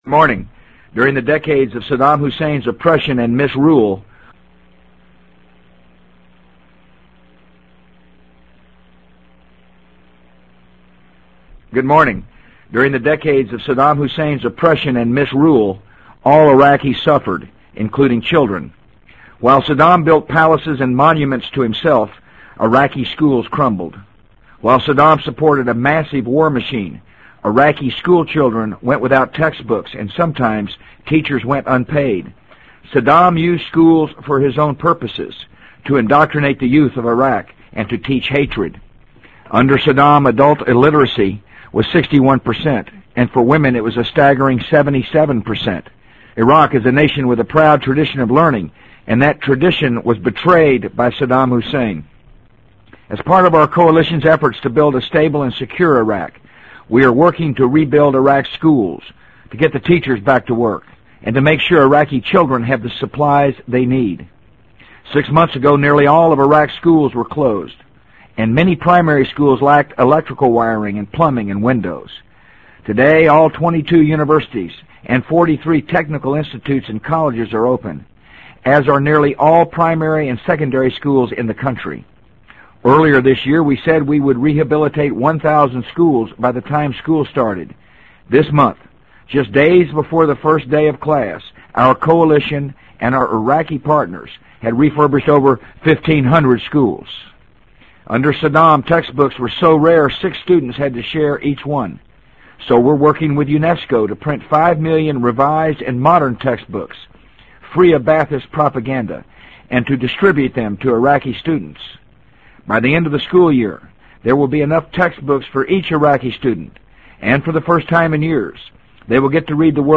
【美国总统George W. Bush电台演讲】2003-10-18 听力文件下载—在线英语听力室